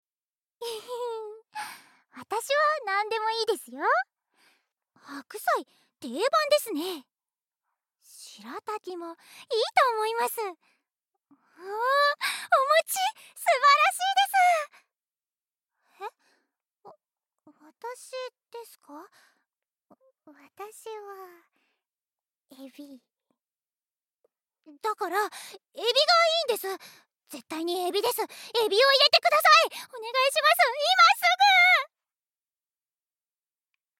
ボイスサンプル
天真爛漫